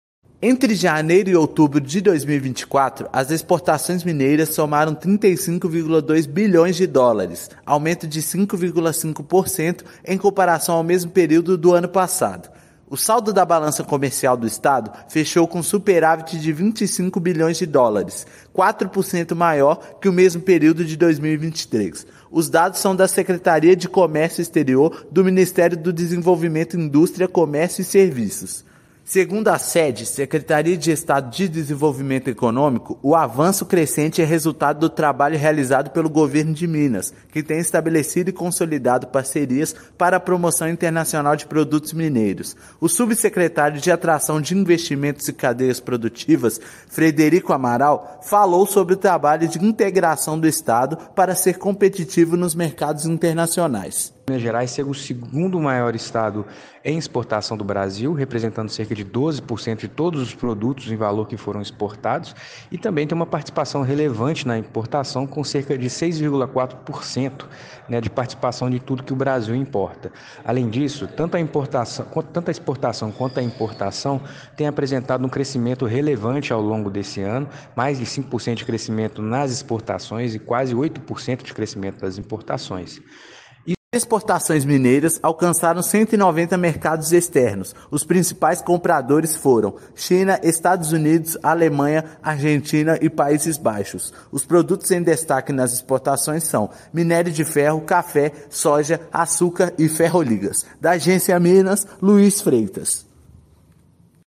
Avanço nos embarques foi de US$ 1,8 bilhão no acumulado do ano em relação a igual período de 2023; balança comercial do estado registrou superávit de US$ 21 bilhões. Ouça matéria de rádio.